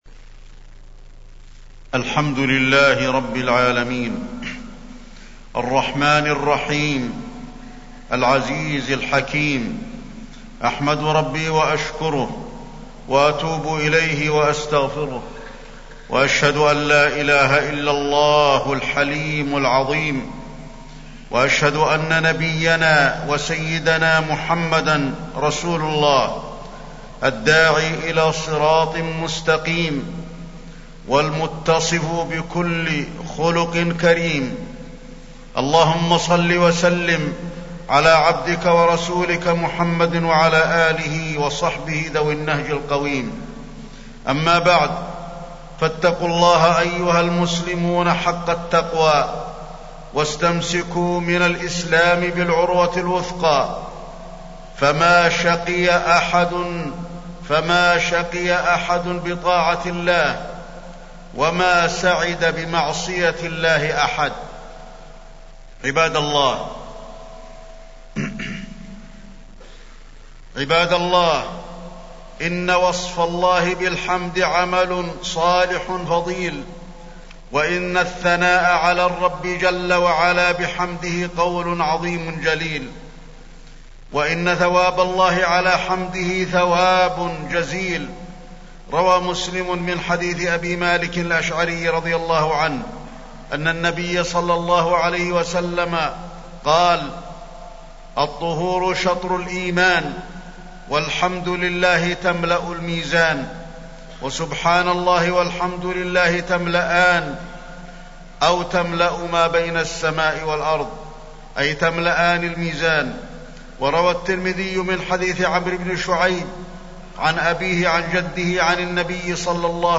تاريخ النشر ٢٨ شوال ١٤٢٨ هـ المكان: المسجد النبوي الشيخ: فضيلة الشيخ د. علي بن عبدالرحمن الحذيفي فضيلة الشيخ د. علي بن عبدالرحمن الحذيفي الخلق الحسن